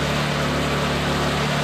techage_generator.ogg